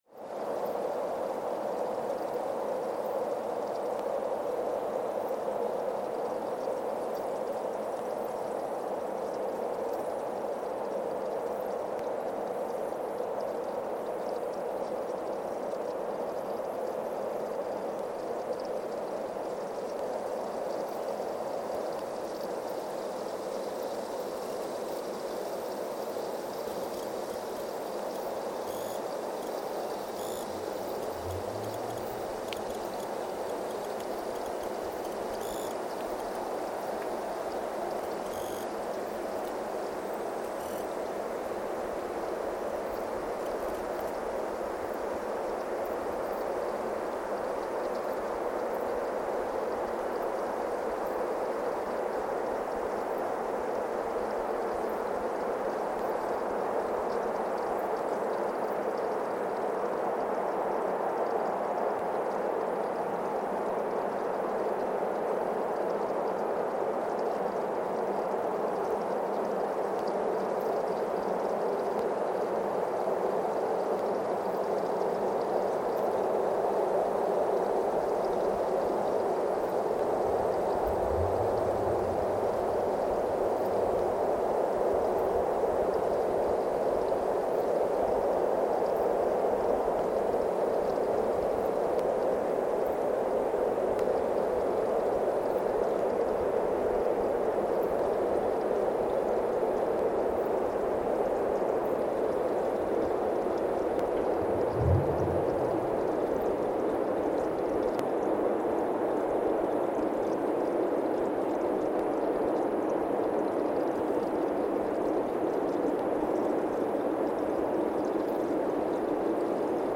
Weston, MA, USA (seismic) archived on December 26, 2024
Sensor : CMG-40T broadband seismometer
Speedup : ×1,800 (transposed up about 11 octaves)
Loop duration (audio) : 05:36 (stereo)
SoX post-processing : highpass -2 90 equalizer 300 2q -6 equalizer 400 2q -6 equalizer 90 12q 6